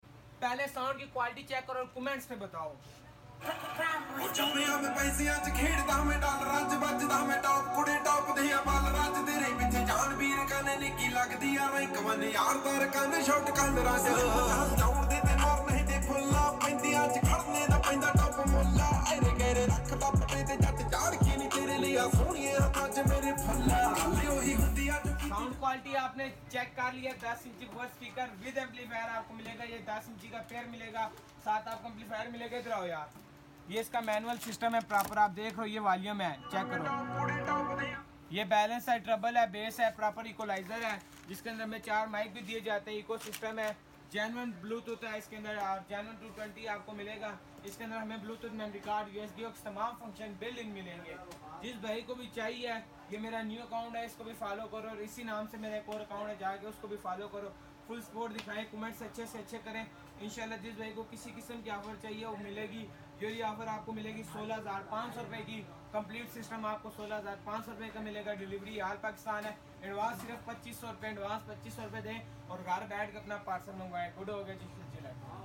10 inches woofer speaker with sound effects free download